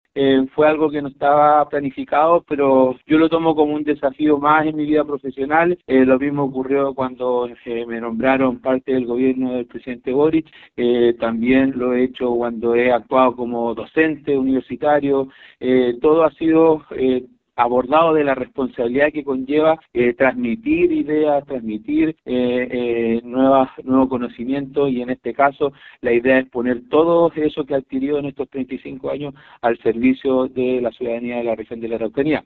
En conversación con Radio Bío Bío, la ahora exautoridad dijo que no estaba planificado, pero que asume el desafío.